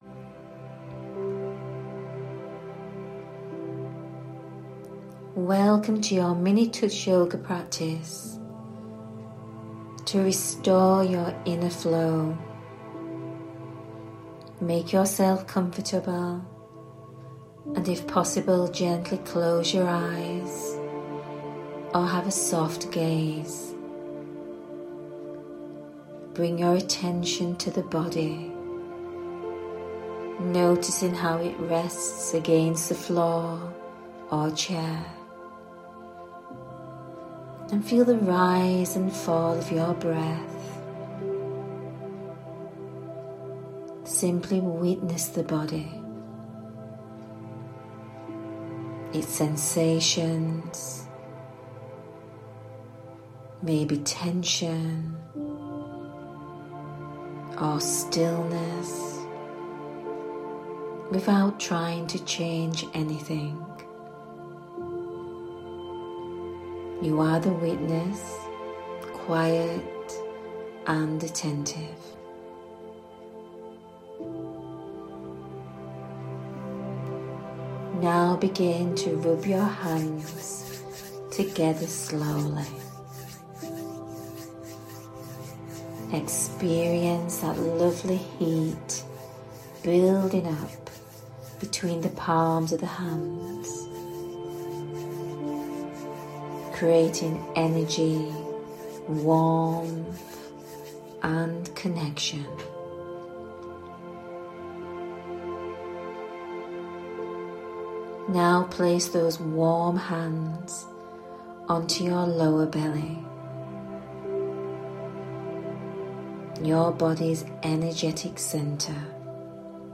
Try this very relaxing guided 5 Minute Touch Yoga™ practice to restore your inner flow (softening what has been held and restoring fluidity)